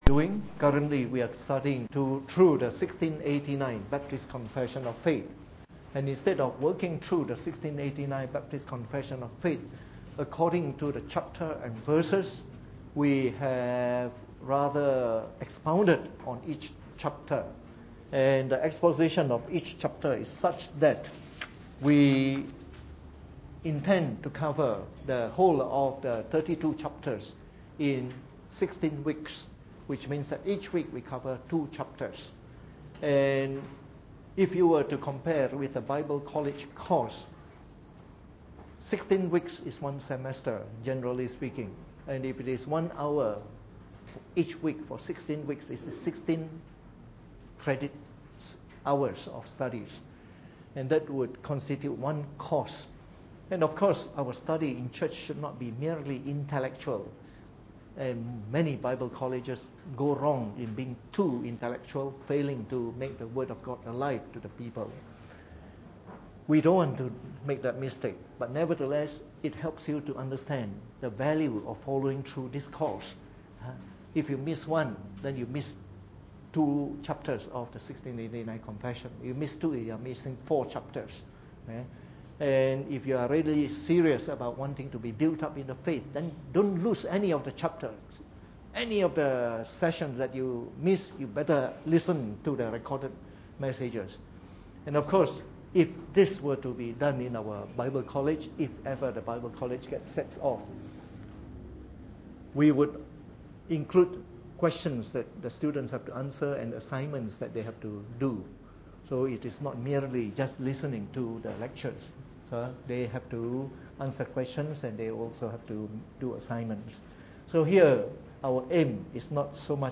Preached on the 30th of March 2016 during the Bible Study, from our series on the Fundamentals of the Faith (following the 1689 Confession of Faith).